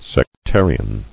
[sec·tar·i·an]